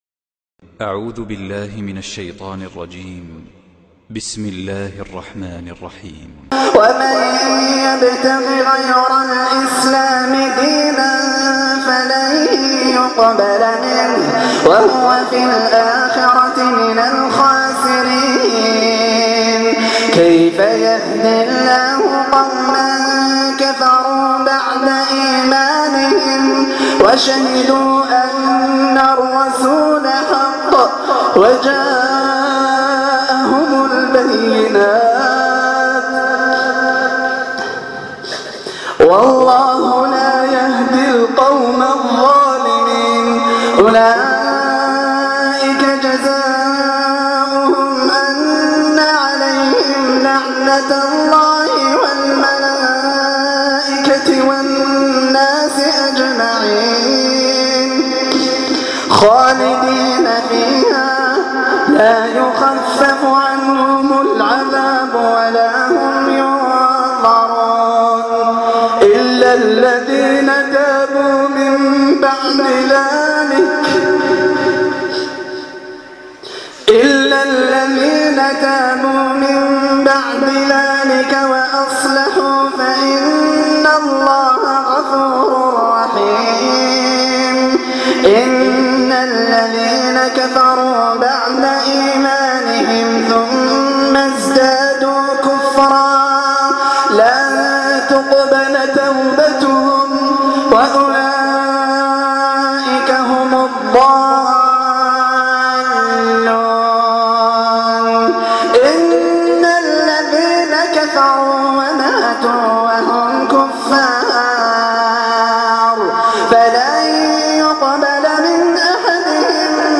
تلاواة مختارة من روائع التلاوات من صلاة التراويح